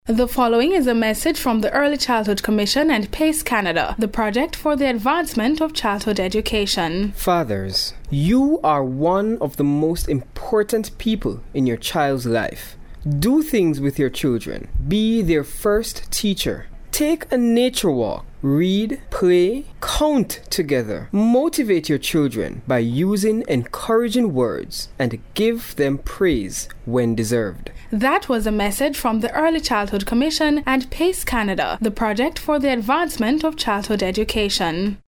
PACE Sponsors Public Service Announcements in Jamaica
The grant from PACE Canada provided funding for strategy sessions with ECC, script writing, recording, production and promotion of the audio pieces.